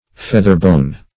Search Result for " featherbone" : The Collaborative International Dictionary of English v.0.48: Featherbone \Feath"er*bone`\, n. A substitute for whalebone, made from the quills of geese and turkeys.